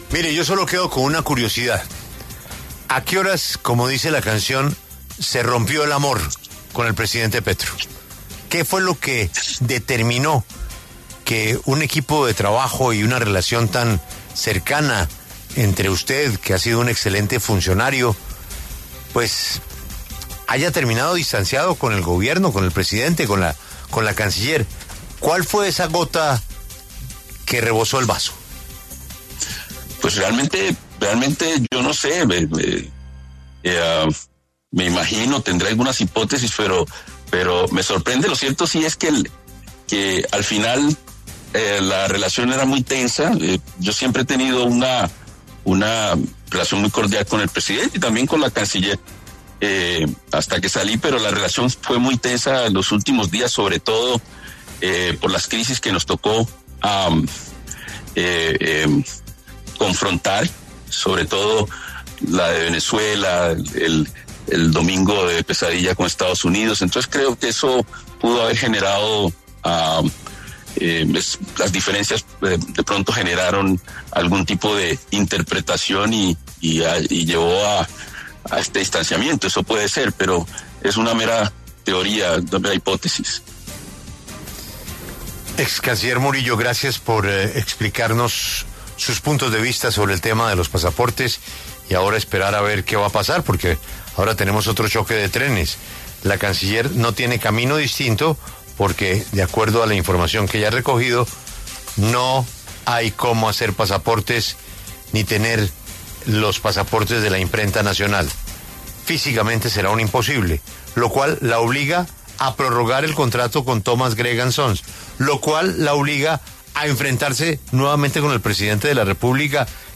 El excanciller Luis Gilberto Murillo habló en los micrófonos de La W, con Julio Sánchez Cristo, sobre su salida de la Cancillería y la relación que tuvo con el presidente Gustavo Petro y en su momento la jefa de Gabinete, Laura Sarabia.